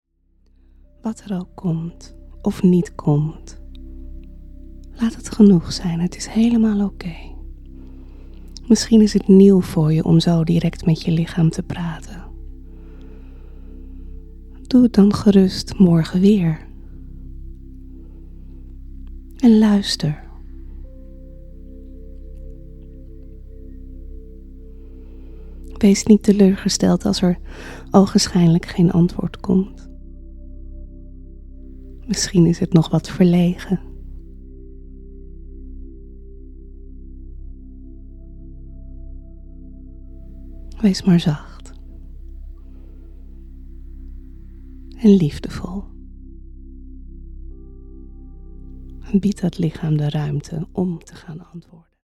Meditatie “IK BEN veilig in mij”